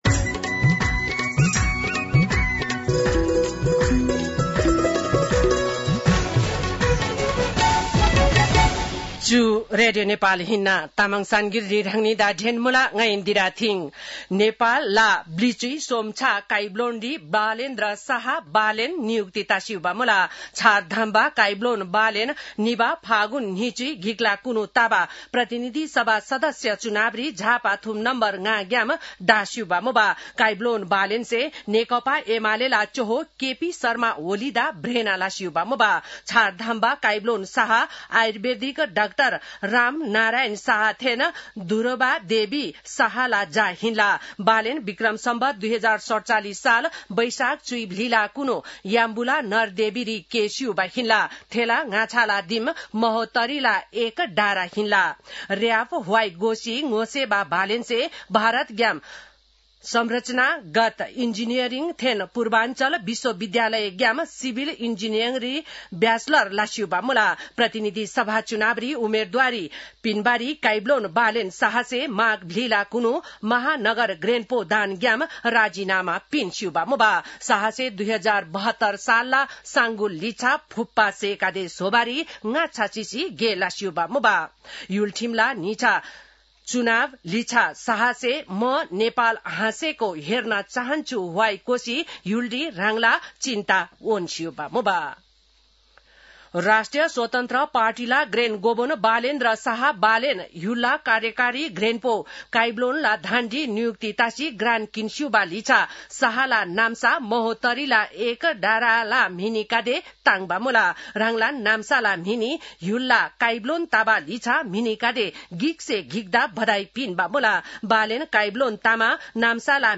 तामाङ भाषाको समाचार : १३ चैत , २०८२